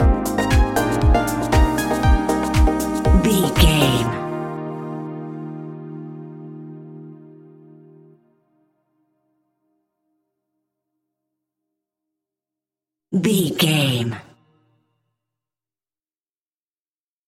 Ionian/Major
E♭
uplifting
energetic
bouncy
piano
electric piano
drum machine
synthesiser
house
electro house
synth leads
synth bass